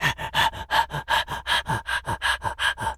wolf_breathing_02.wav